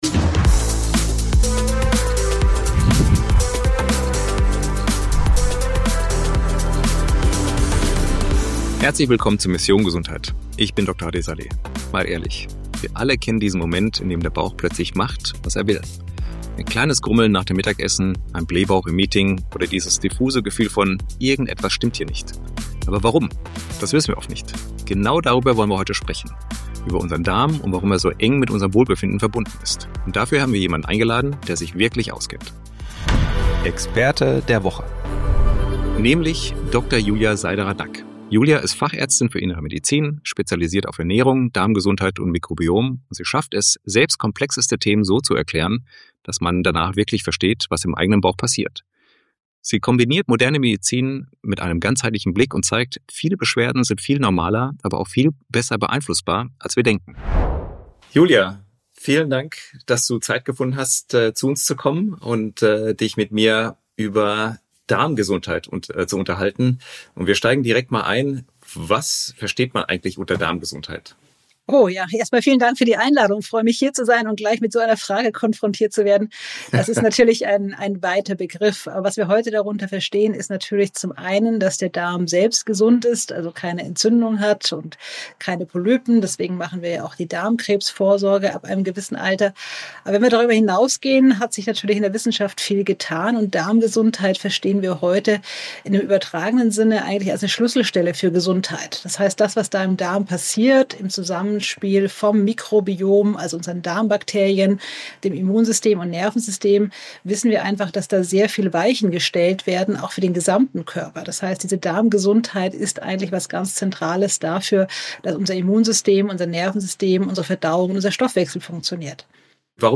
Ein Gespräch über evidenzbasierte Medizin, das Mikrobiom und darüber, warum echte Prävention im Alltag beginnt und nicht erst in der Arztpraxis.